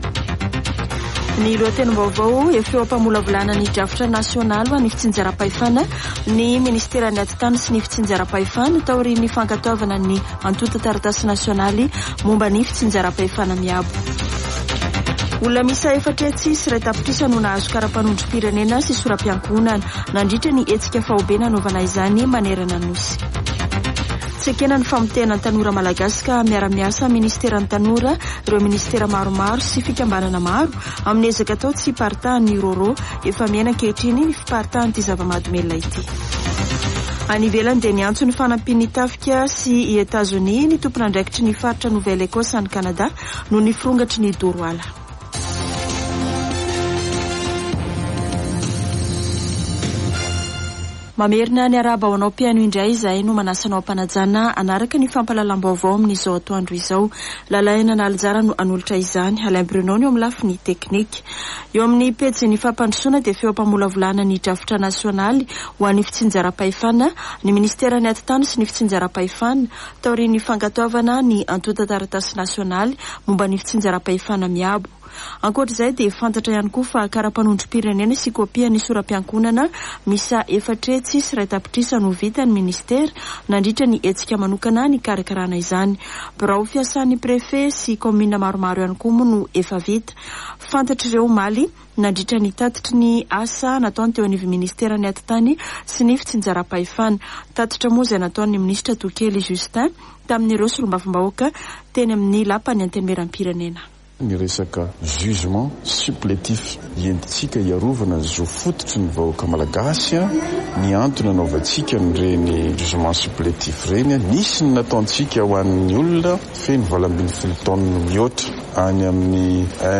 [Vaovao antoandro] Alakamisy 1 jona 2023